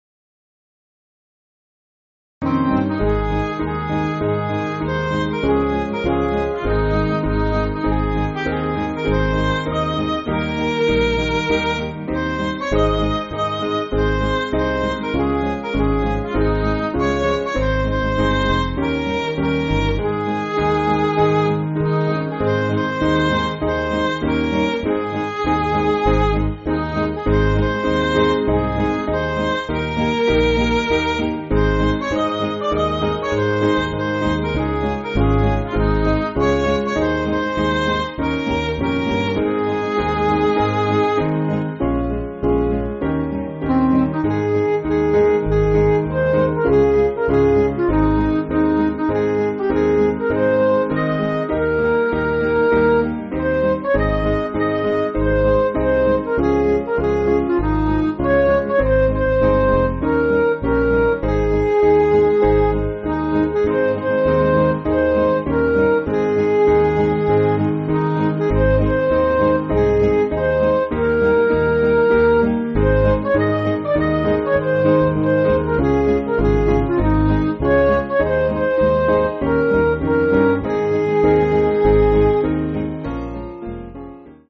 Piano & Instrumental
(CM)   4/Ab